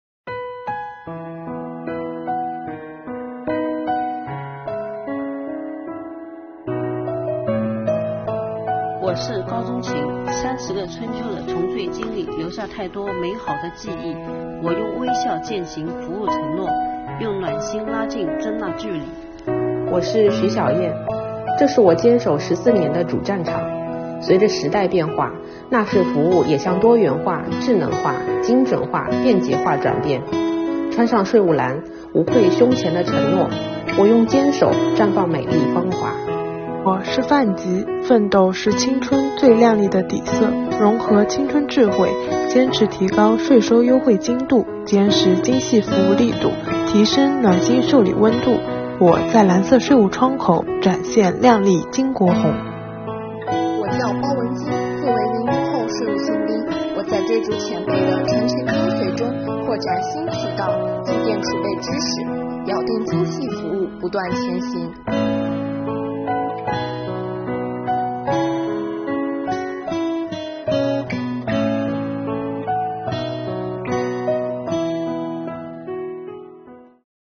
每个时代都有主题，每一代人都肩负着时代使命，一代代“税务蓝”用真心和奉献，绘就服务为民的鲜红底色。点击视频，倾听新时代税务女性的心声！